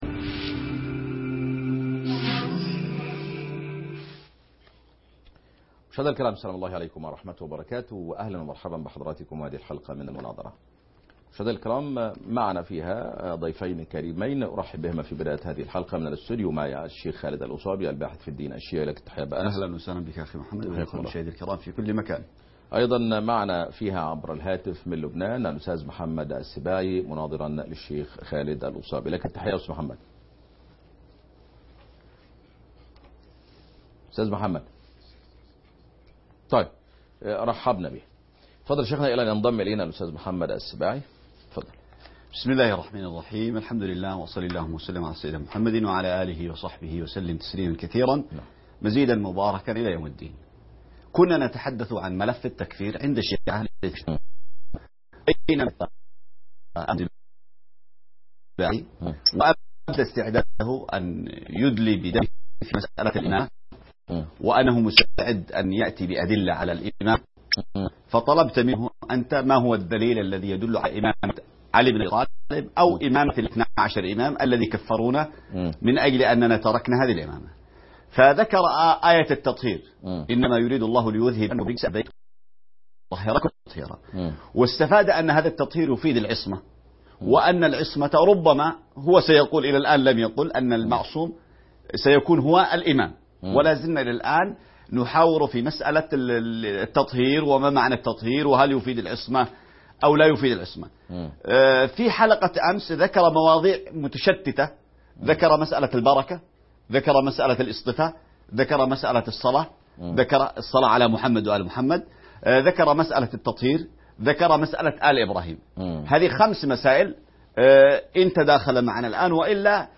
مناظرة حول آية التطهير